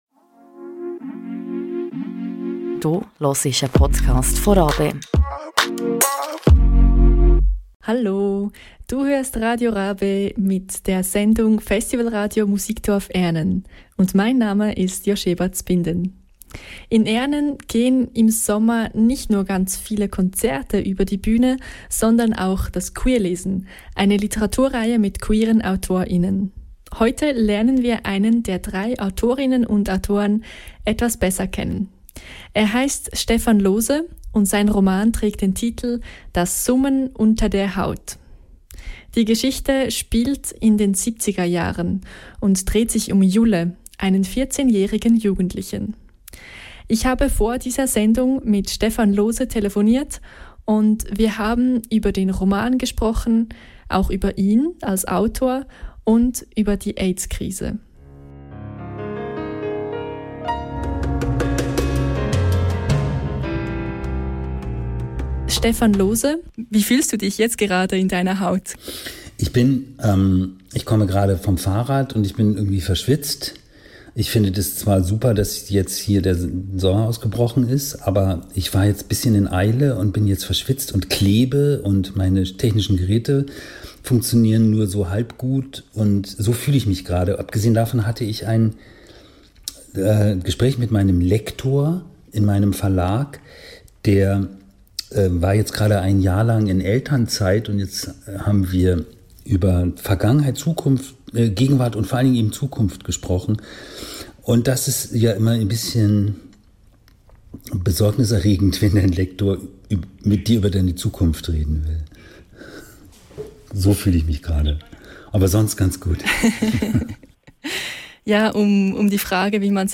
Im Interview spricht er übers Schreiben, über seinen Roman und über die Aids-Krise.